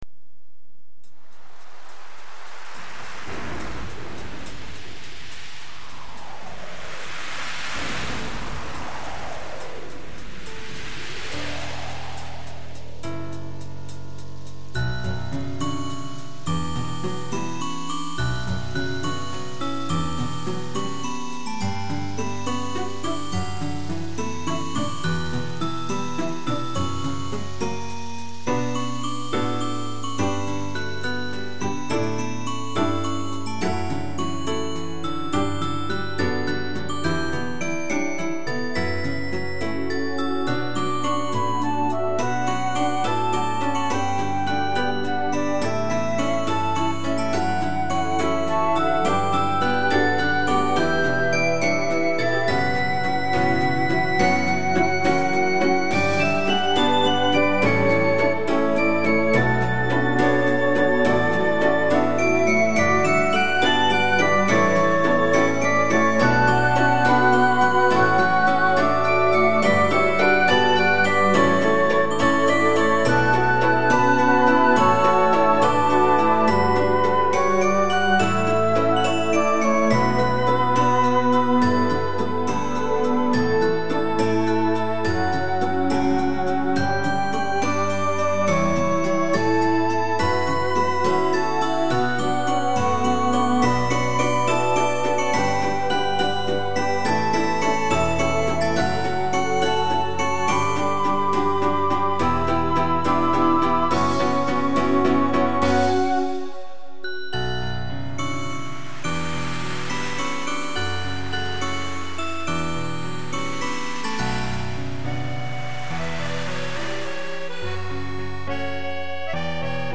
ワクワクするような、懐かしいような、不安定のような、 不思議な情感が沢山詰まっている曲。 ダウンロード ※ ダウンロードカウンタ に反映 ファイル ファイル y08_04.mp3 作品名 My Dear Admiral...